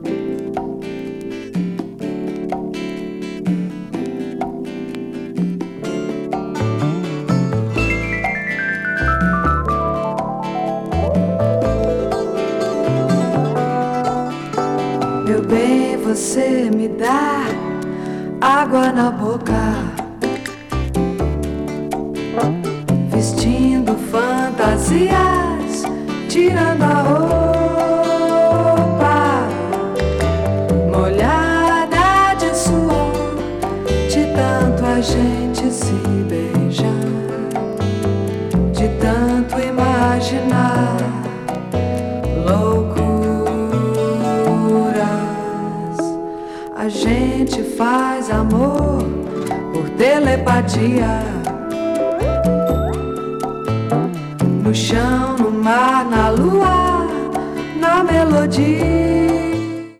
70s LATIN / BRASIL 詳細を表示する